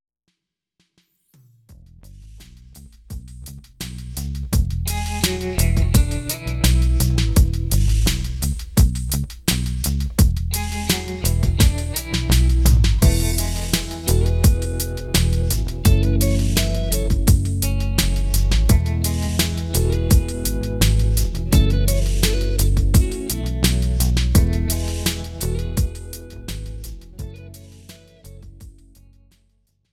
This is an instrumental backing track cover
• Key – E
• Without Backing Vocals
• No Fade